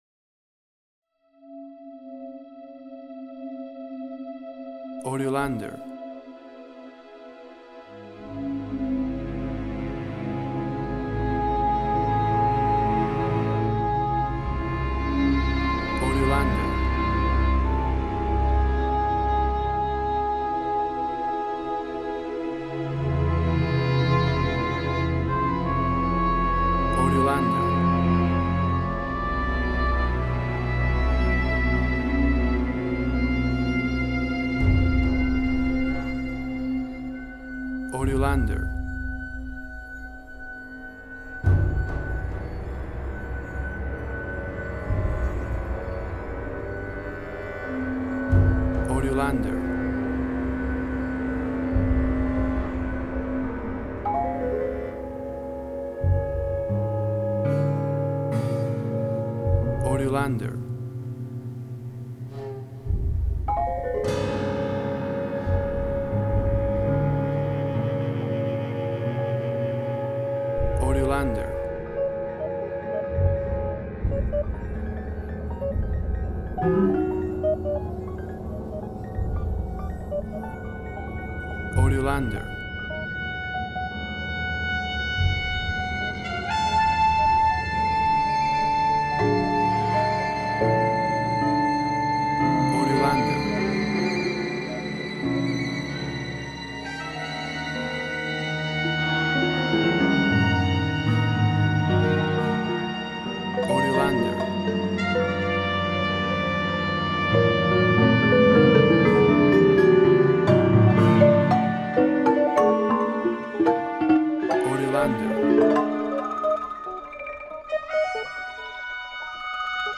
Dark gloomy.